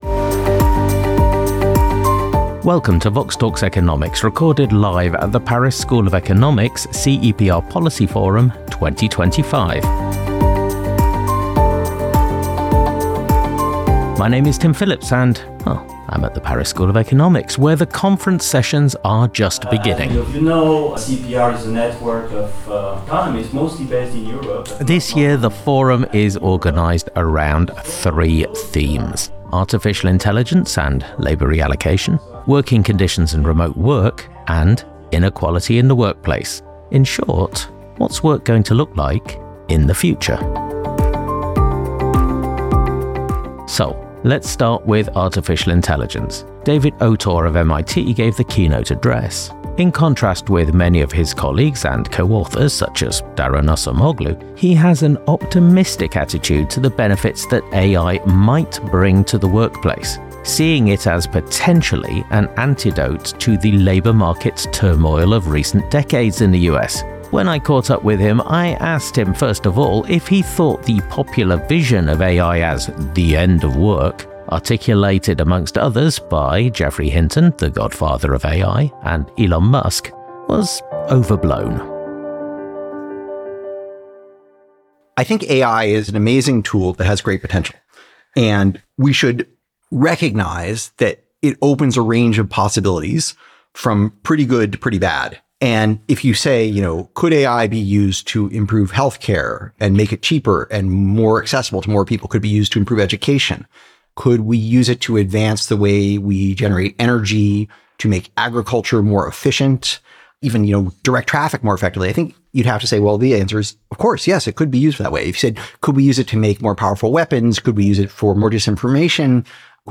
Recorded live at the PSE-CEPR Policy Forum 2025.